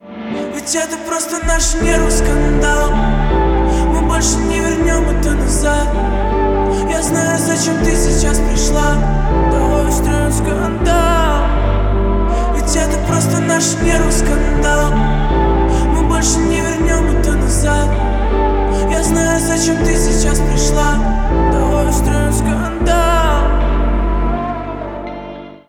• Качество: 128, Stereo
пианино